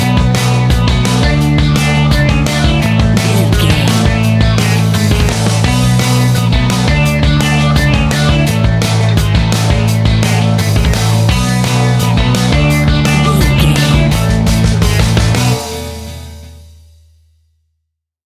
Mixolydian
fun
energetic
uplifting
instrumentals
upbeat
groovy
guitars
bass
drums
piano
organ